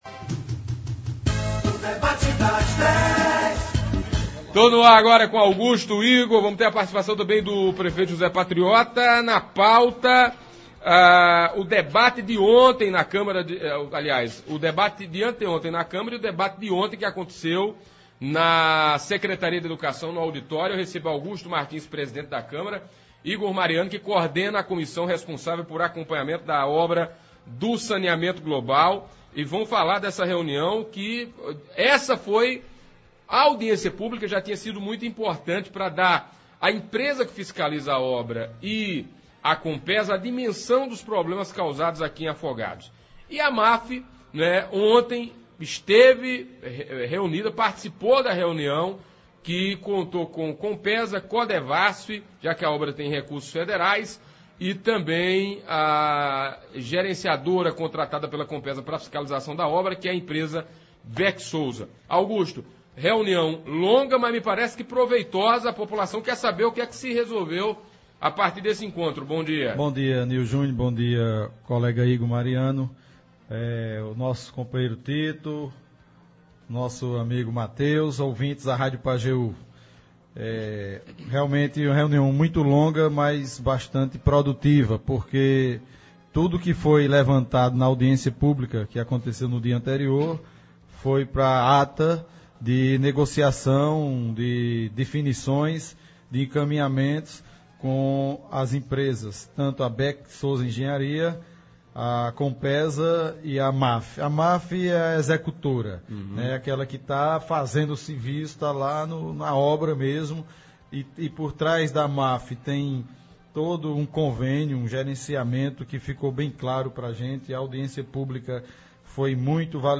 Hoje (13) nos estúdios da Pajeú, o presidente da Câmara de Vereadores de Afogados da Ingazeira, Augusto Martins e o vereador Igor Sá Mariano, esclareceram alguns pontos discutidos durante a Audiência Pública que aconteceu na manhã da última terça (11) e a reunião da tarde de ontem (12) e responderam algumas dúvidas dos ouvintes e internautas.